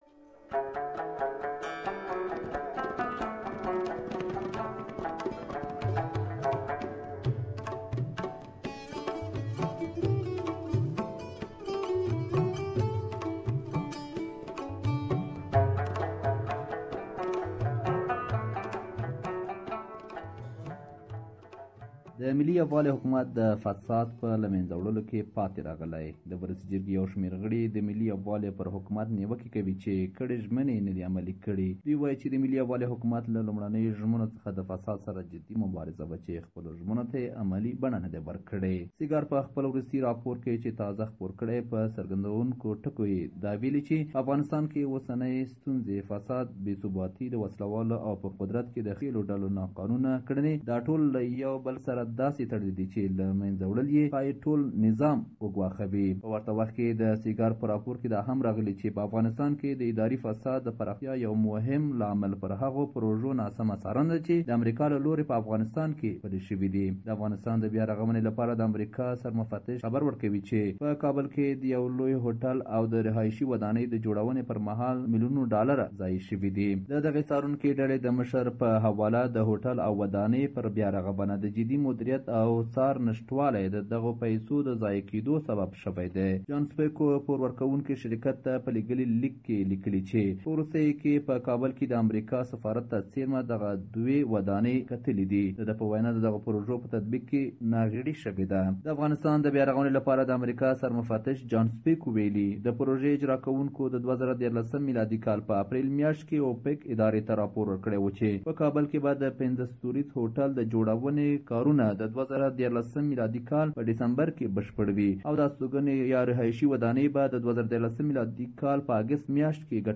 The Afghan Unity Government has promised that they will eradicate corruption in Afghan institutions, yet some believe that the government has failed in delivering on its promise. We have a report that you can listen to here.